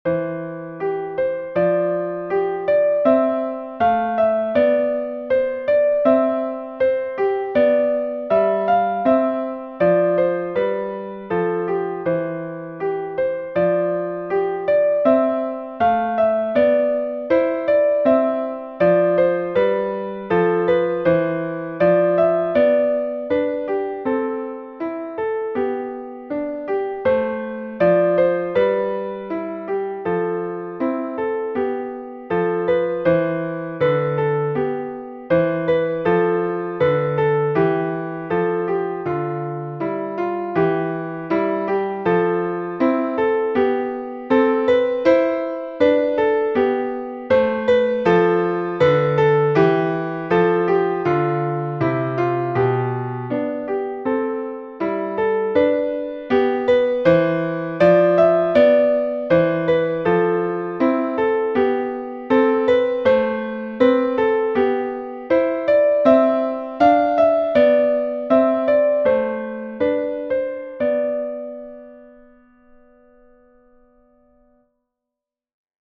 easy and engaging piano solos
Instructional, Medieval and Renaissance